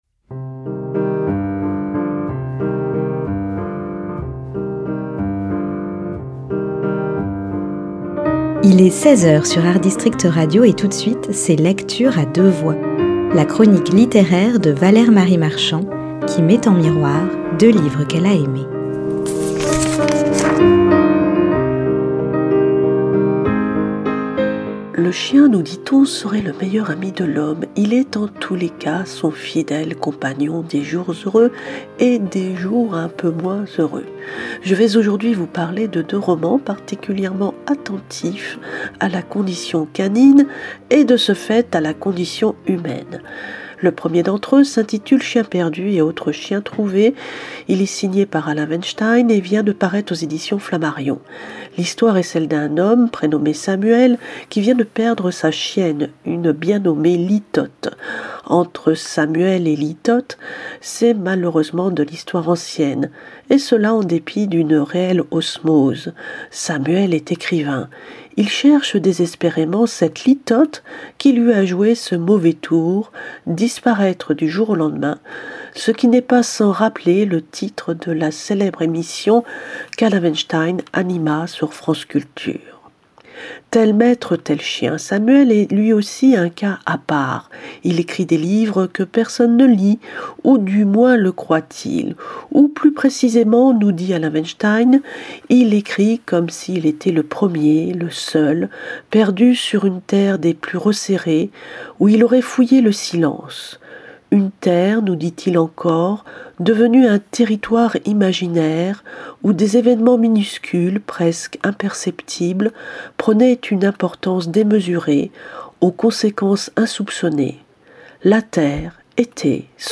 LECTURE A DEUX VOIX, mardi et vendredi à 10h et 16h.